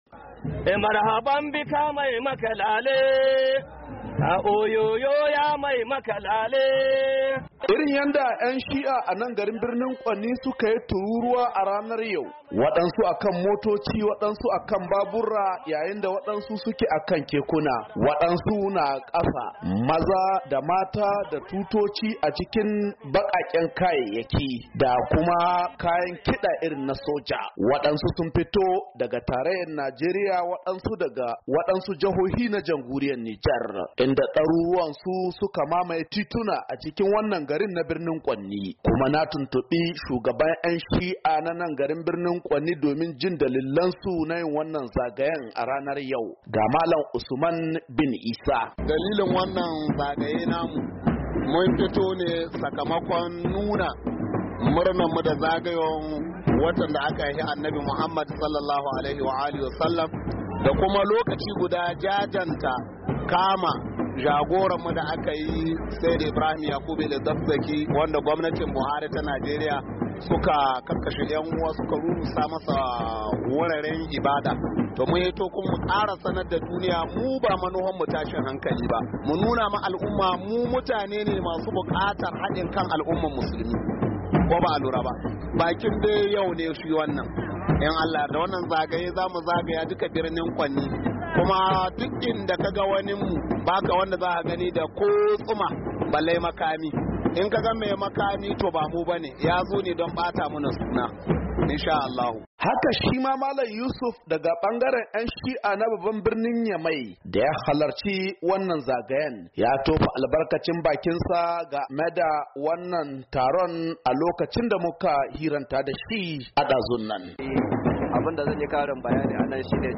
suna nunawa duniya irin zalincin da aka yi musu ne. ga muryar rahoton da ya aiko mana.